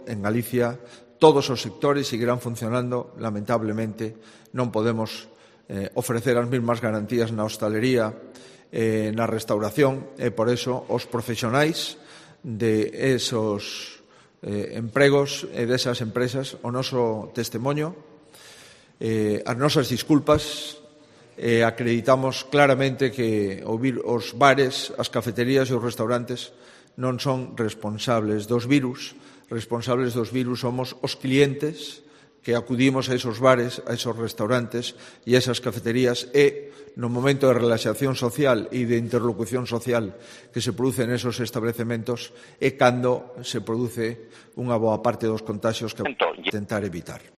Declaraciones de Núñez Feijóo sobre la expansión del coronavirus en los establecimientos de hostelería